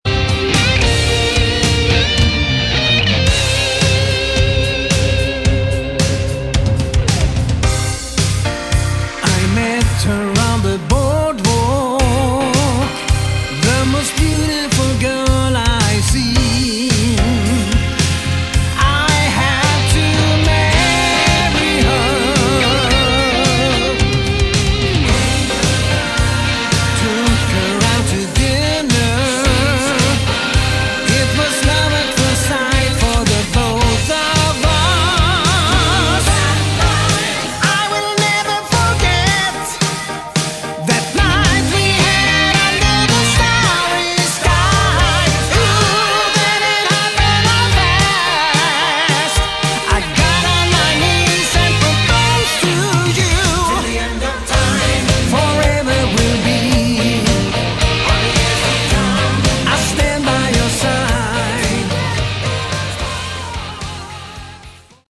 Category: AOR
drums, keyboards
lead guitars, rhythm guitars, bass
vocals